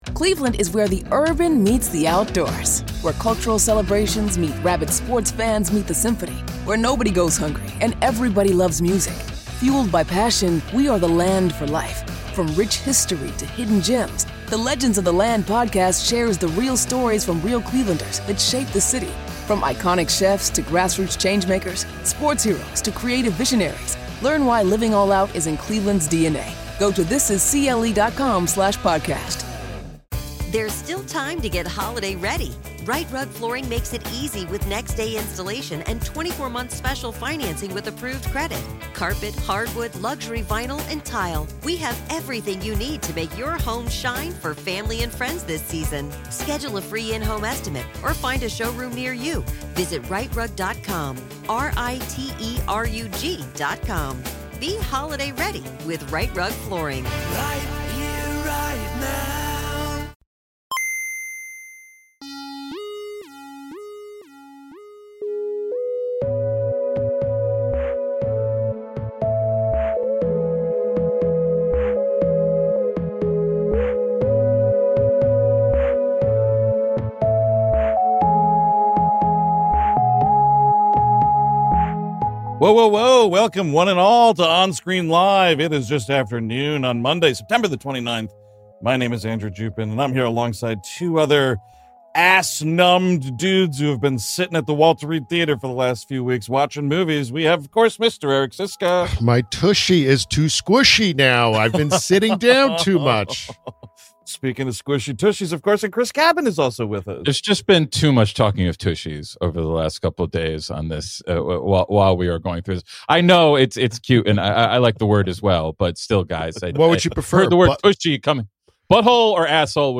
Originally live-streamed Monday, September 29, 2025 On this On-Screen Live special